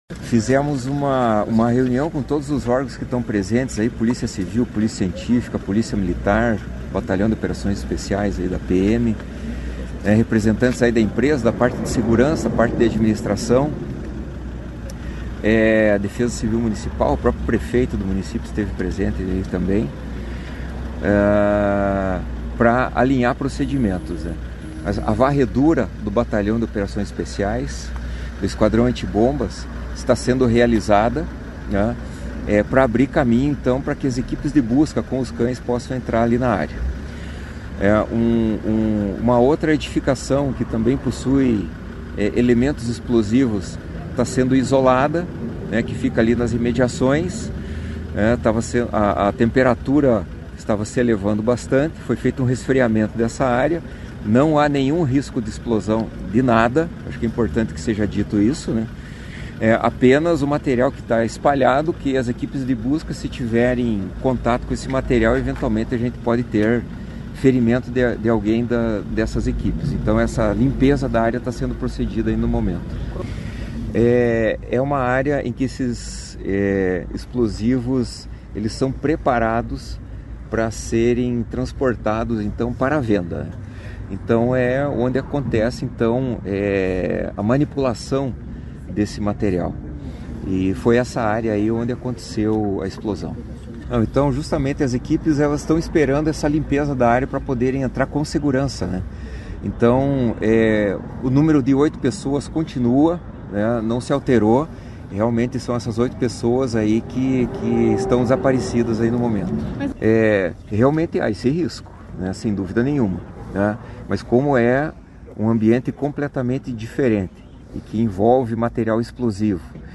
Sonora do comandante-geral do Corpo de Bombeiros, coronel Antonio Geraldo Hiller, sobre a explosão em fábrica de explosivos na RMC | Governo do Estado do Paraná